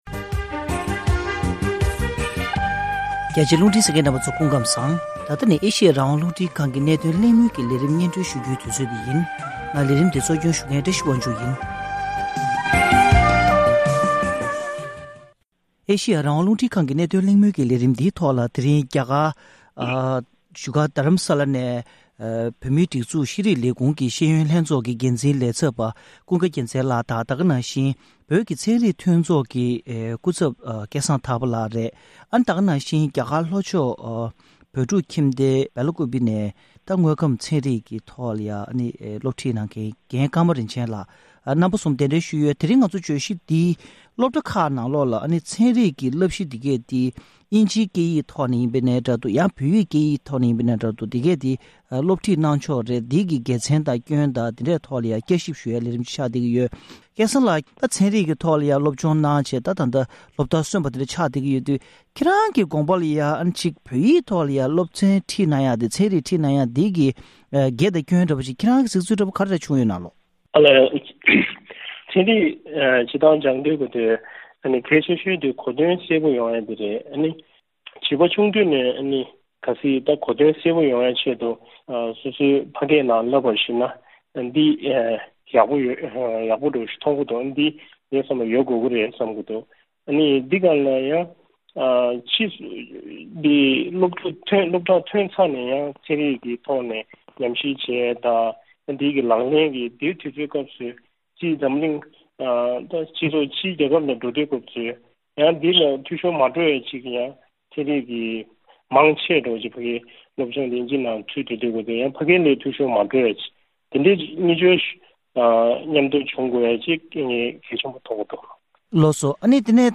བཙན་བྱོལ་བོད་མིའི་སློབ་གྲྭ་ཁག་གི་ནང་ཚན་རིག་སློབ་ཚན་བོད་ཡིག་ནང་སློབ་འཁྲིད་གནང་བའི་དགེ་སྐྱོན་སྐོར་གླེང་བ།